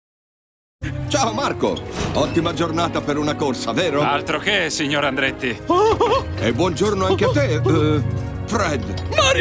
• "Cars - Motori ruggenti" (Voce di Mario Andretti)